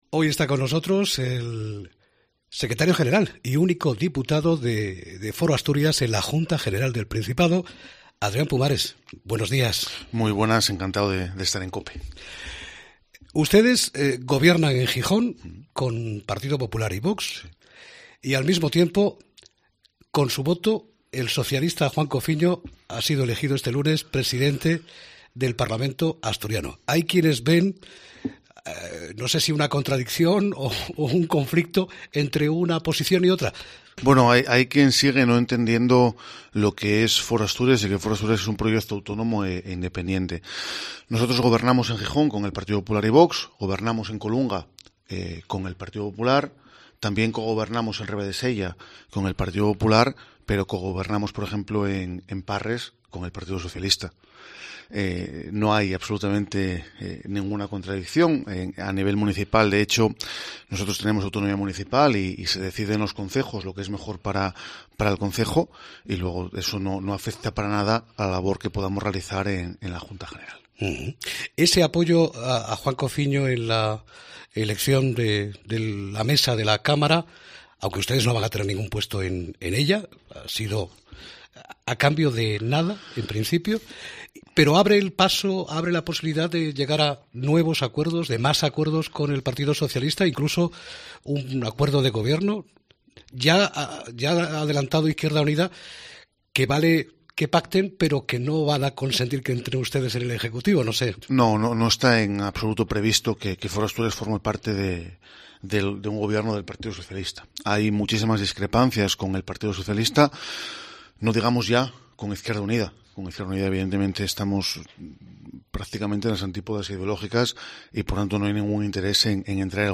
Entrevista con Adrián Pumares, secretario general y diputado de Foro Asturias
El secretario general y único diputado de FORO Asturias en la Junta General del Principado, Adrián Pumares, ha estado este miércoles en los micrófonos de Herrera en COPE Asturias, donde ha descartado la posibilidad de un pacto de gobierno con el PSOE o su entrada en el ejecutivo que presidirá Adrián Barbón.